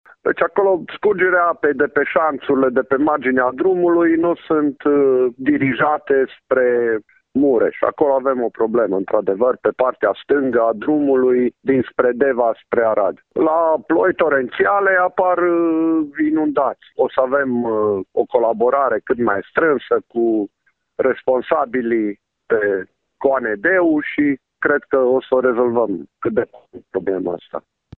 Primarul oraşului Lipova, Mircea Jichici, spune că probleme au fost semnalate în special pe câteva străzi pe care apa s-a scurs de pe DN7, care este mai înalt.